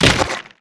赤手空拳击中肉体16-YS070524.wav
通用动作/01人物/03武术动作类/空拳打斗/赤手空拳击中肉体16-YS070524.wav
• 声道 單聲道 (1ch)